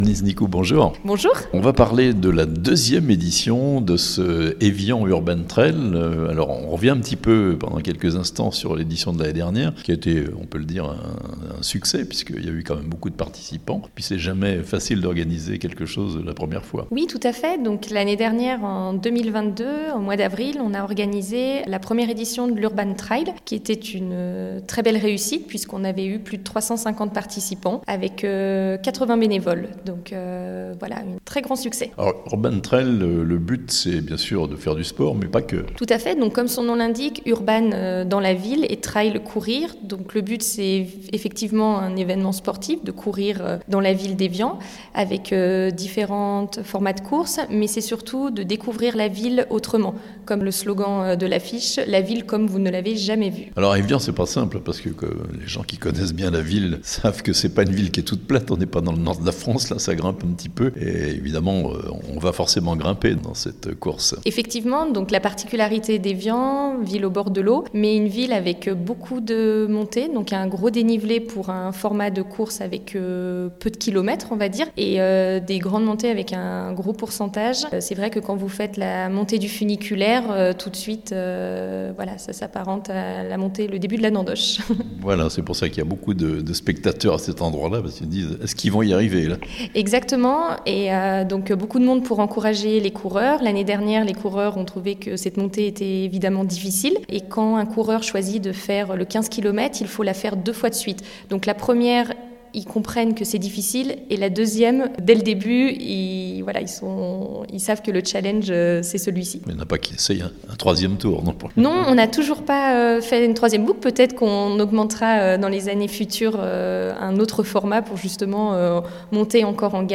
Evian : avec l'Urban Trail 2023, la ville comme vous ne l'avez jamais vue ! (interview)